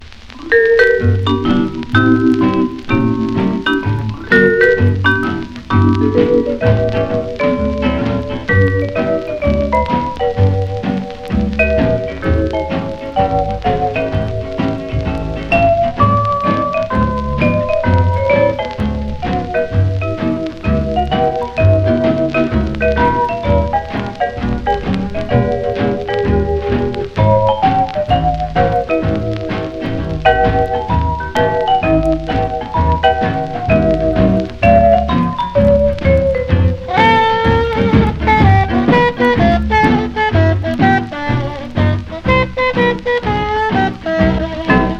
Jazz　Sweden　12inchレコード　33rpm　Mono